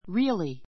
really 小 A1 rí(ː)əli リ (ー)アり 副詞 （ ⦣ 比較変化なし） ❶ 本当に , 実際に （truly） ; 本当は , 実は （in fact） John's father was a really great man.